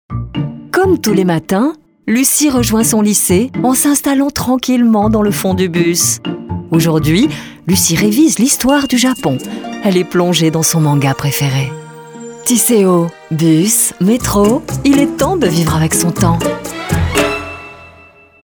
Sprecherin französisch (Muttersprachlerin) warm, smoth and secure, serious, friendly, smilee, fresh intentions
Sprechprobe: Werbung (Muttersprache):
Professional French native voice over: warm, smoth and secure, serious, friendly, smilee, fresh intentions